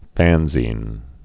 (fănzēn)